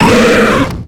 Cri de Grotadmorv dans Pokémon X et Y.